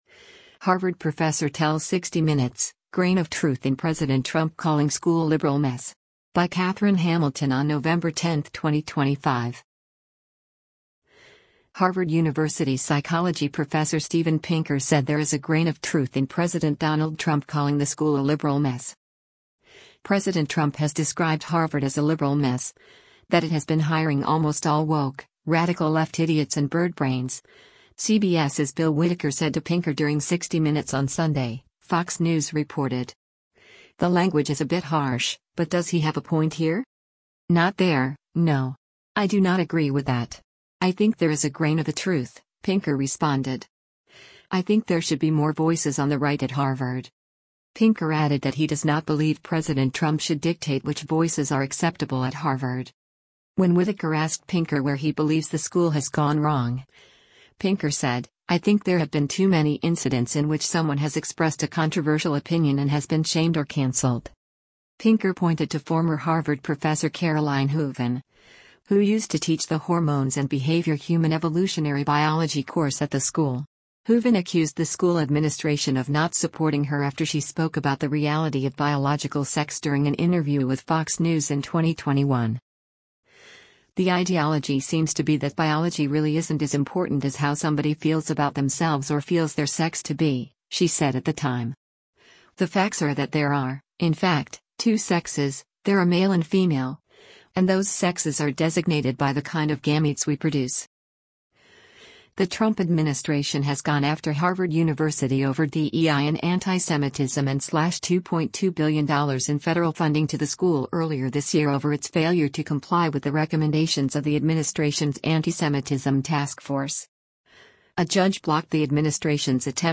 “President Trump has described Harvard as a liberal mess, that it has been hiring almost all woke, radical left idiots and birdbrains,” CBS’s Bill Whitaker said to Pinker during 60 Minutes on Sunday, Fox News reported.